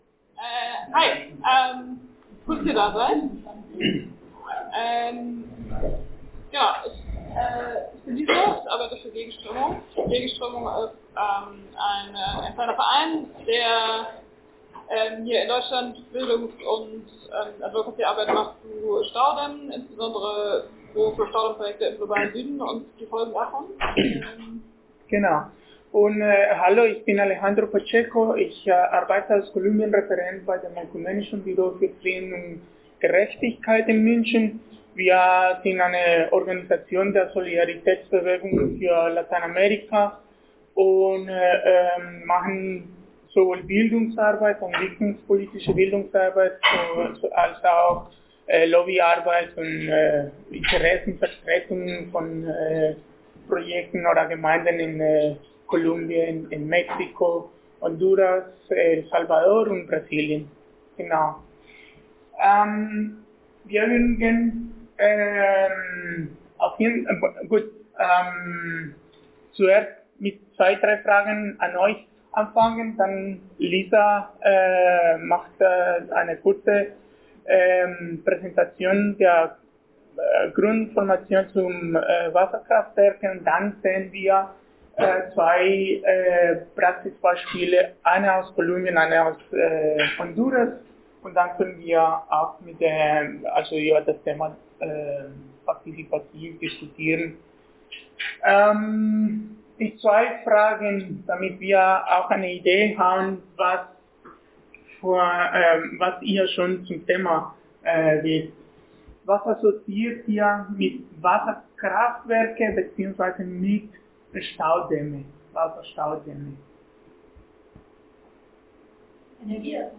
Wasserkonferenz: Workshop – Internationale Solidarität gegen Megastaudämme – radio nordpol